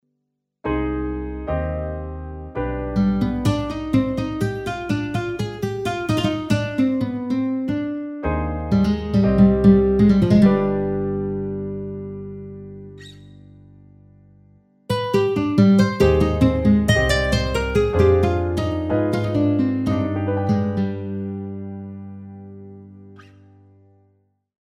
keyboard guitar